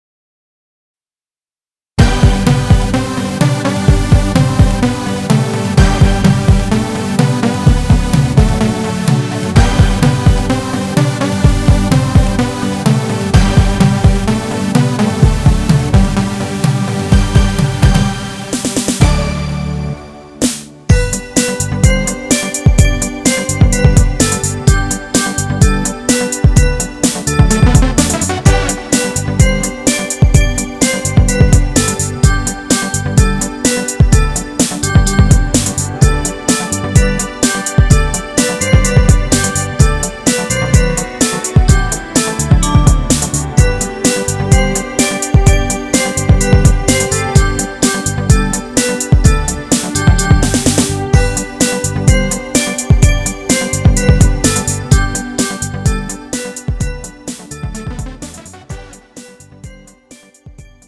음정 G 원키
장르 가요 구분 Pro MR